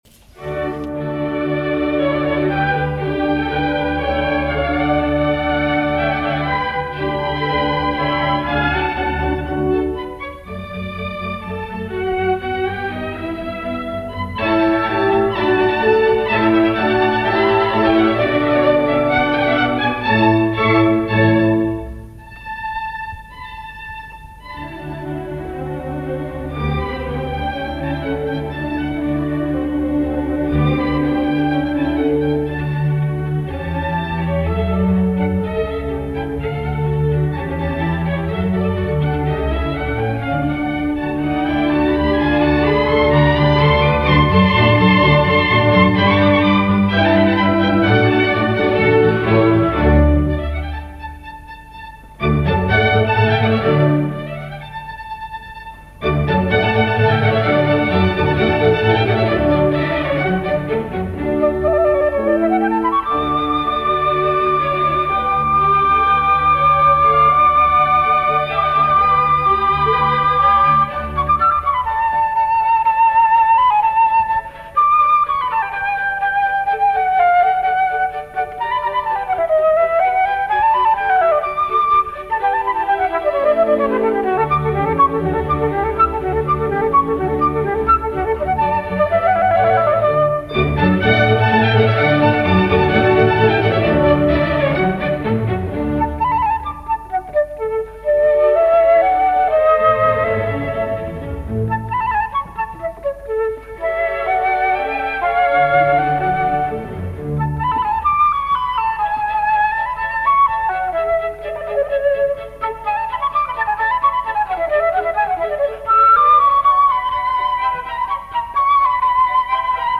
Over to Switzerland this weekend for a performance of the Mozart Flute Concerto in D Major, K 314 with Aurele Nicole and the Lausanne Chamber Orchestra conducted by Victor Desarzens in this 1952 broadcast recording from the Archives of Swiss Radio, recorded December 19, 1952.
The prominent Swiss flautist and pedagogue, Aurèle Nicolet, studied flute and theory with André Jamet and Willy Burckhard in Zürich, then pursued training with Marcel Moyse and Yvonne Drappier at the Paris Conservatoire.